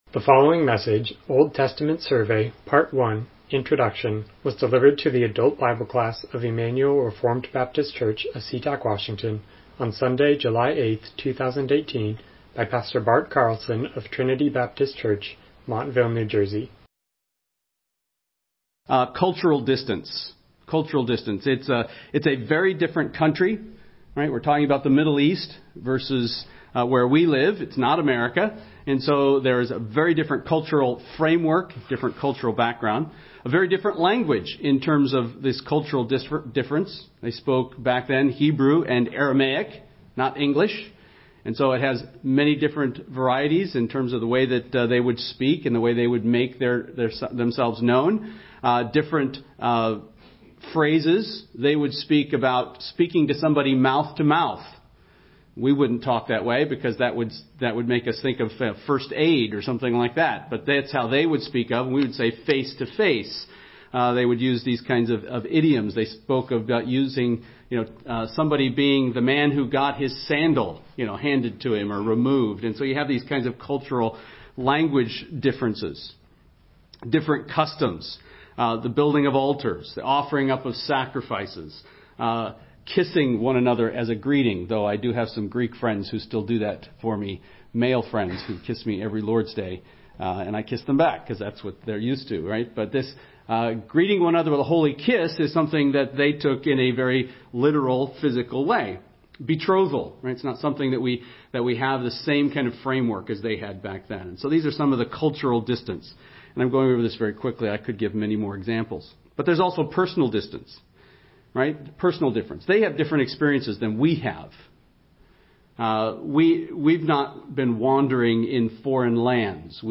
Old Testament Survey Service Type: Sunday School « The Birth of Isaac Happiness Requires Repentance